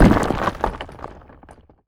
rock_smashable_hit_impact_02.wav